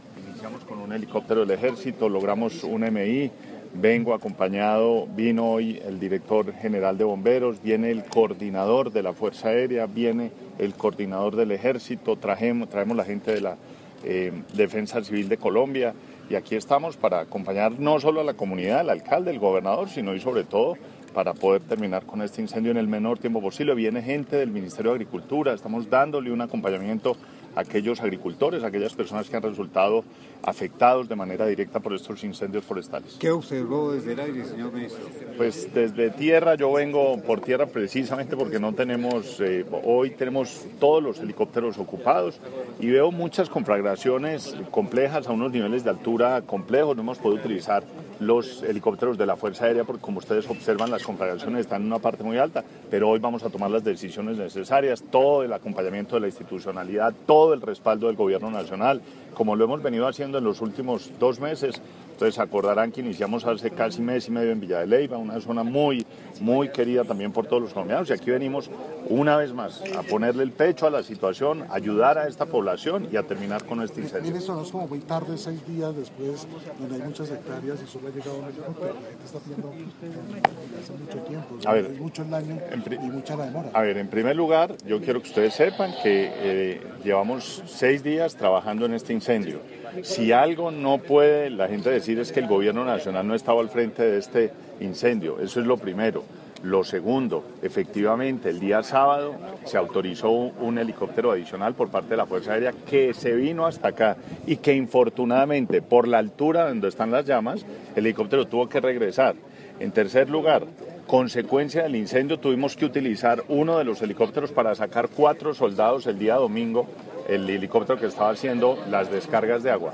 Declaraciones del Ministro de Ambiente y Desarrollo Sostenible, Gabriel Vallejo López
29-Ministro_Nobsa.mp3